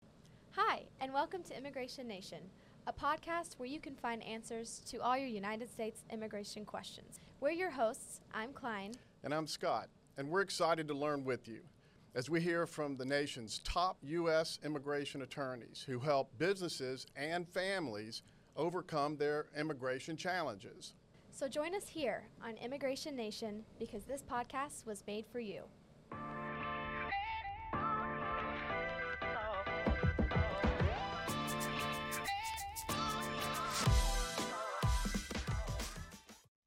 Welcome to Immigration Nation, the podcast that dives deep into the complex and often misunderstood world of United States immigration. Through interviews with leading US immigration Attorneys, this podcast aims to provide a comprehensive overview of the policies, struggles and victories within the ever changing world of immigration.